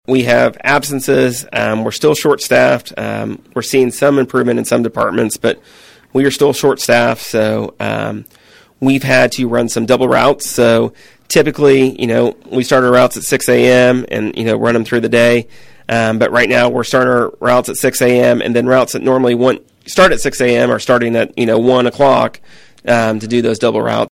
On KVOE’s Morning Show on Wednesday, City Manager Trey Cocking said staffing levels are the reason why, although there are two different reasons in the picture.